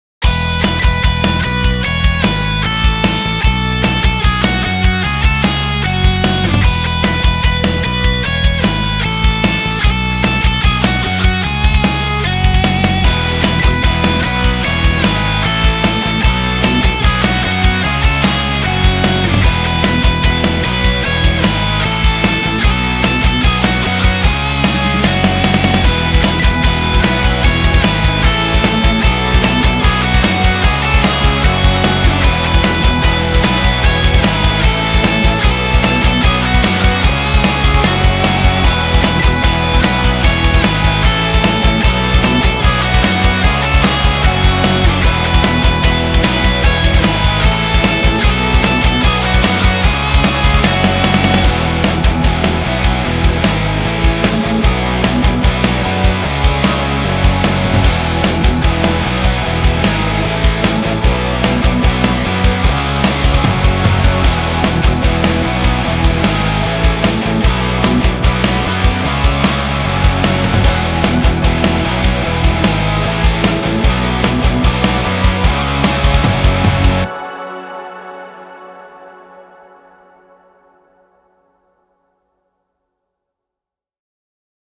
[Metal] Ночь Перед Рождеством)
Никак не могу накрутить весомые ритм-гитары. Возможно тональность слишком высокая? Лид пока что криво сыгран.